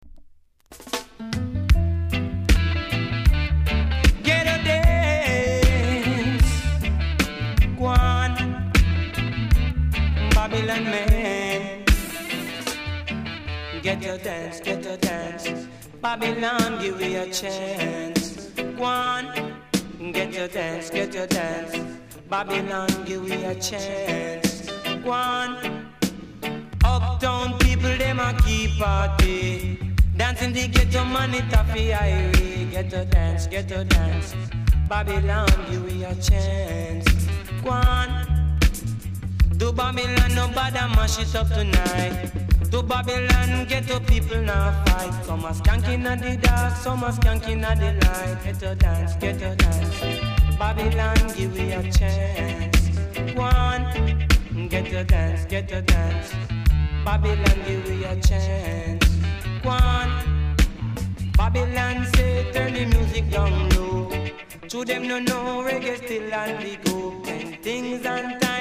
※多少小さなノイズはありますが概ね良好です。
コメント BIG 80's!!